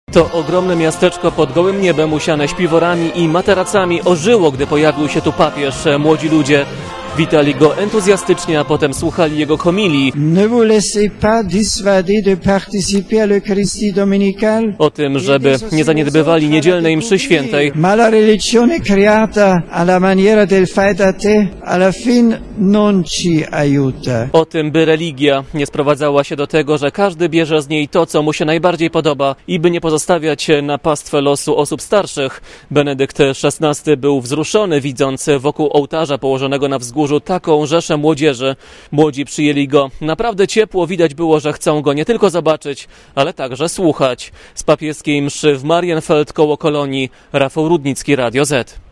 Papieża na błoniach Marienfeld (Pola Maryjne), na mszy kończącej XX Światowe Dni Młodzieży, witało około miliona ludzi.